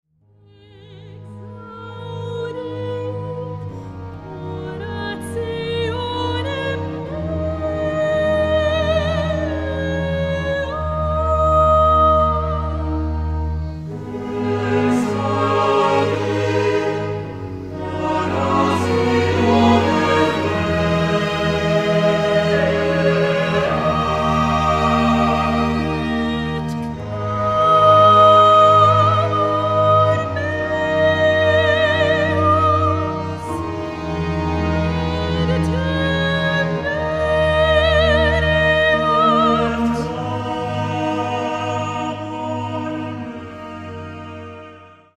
a haunting oratorio
scored for soloists, SATB choir and chamber orchestra